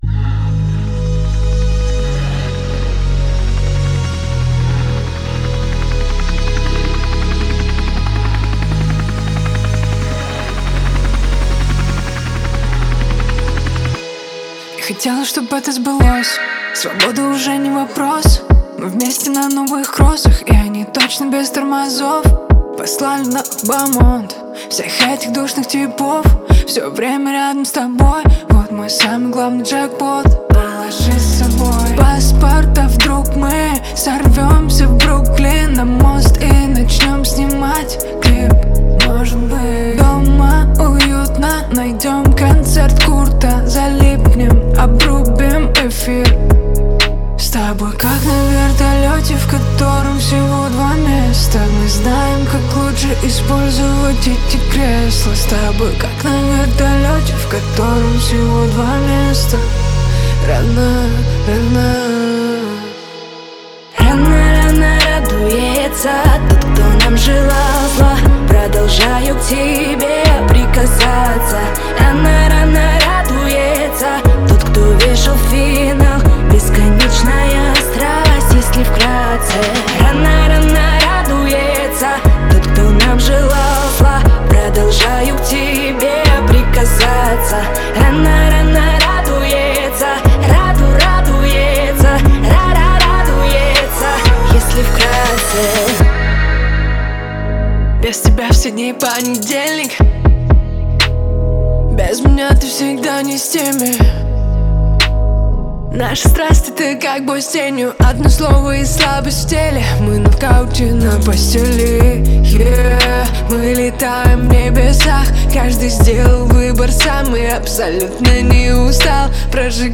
это энергичная песня в жанре поп